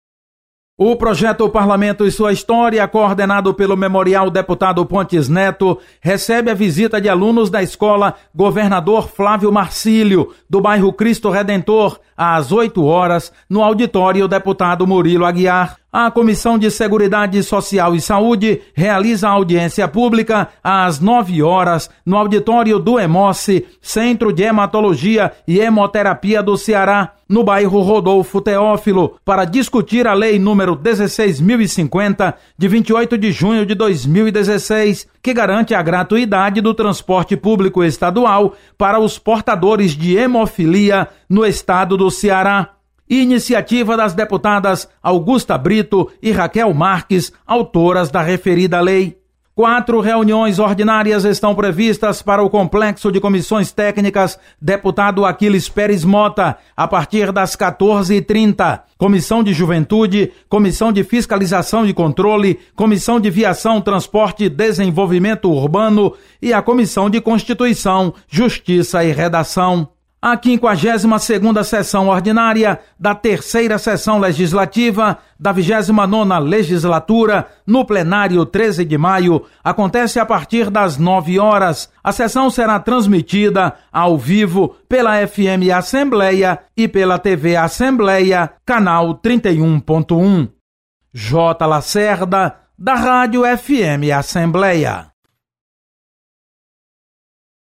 Acompanhe as atividades de hoje da Assembleia Legislativa. Repórter